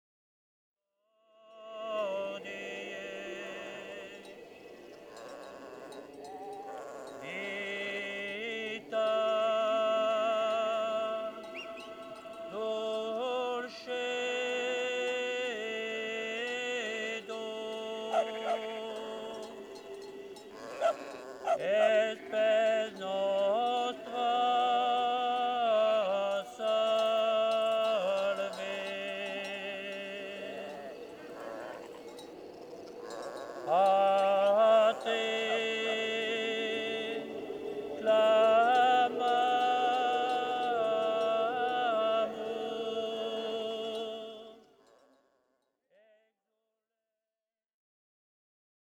Voix du berger